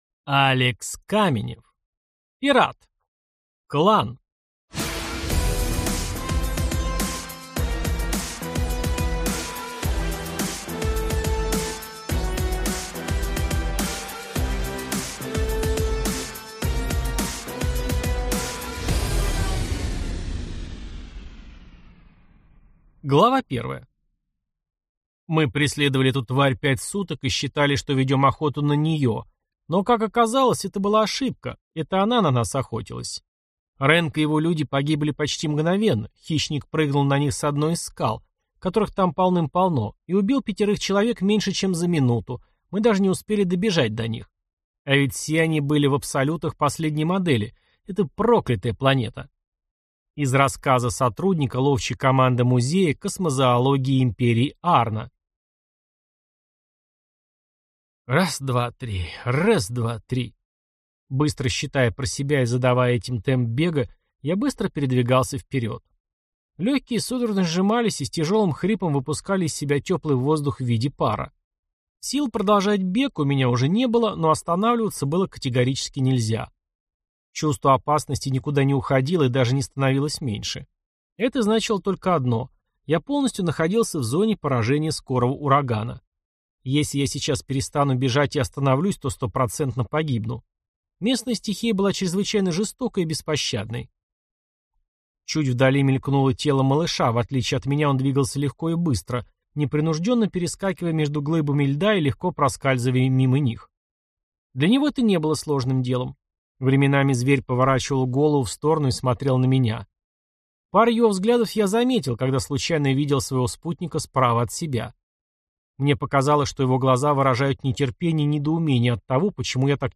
Аудиокнига Пират: Клан | Библиотека аудиокниг